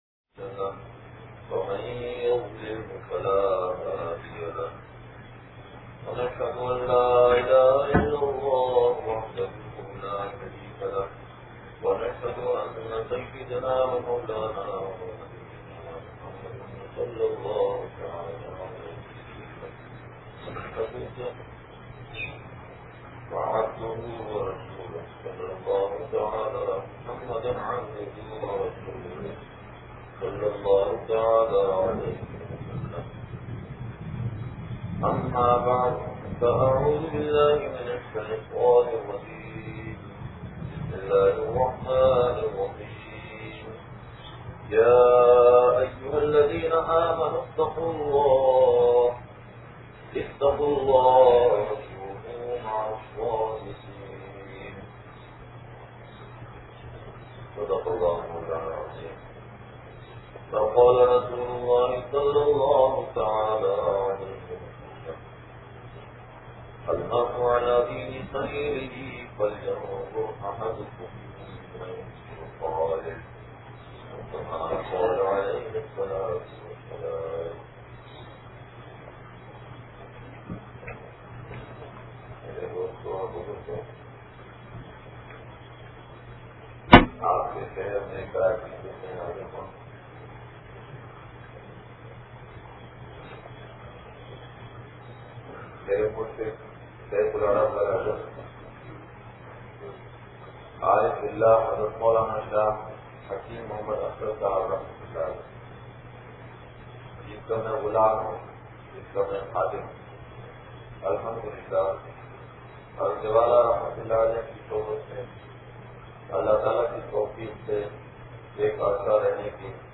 بیان بعد نماز فجر جامع مدینہ مسجد منوا آباد نوابشاہ سندھ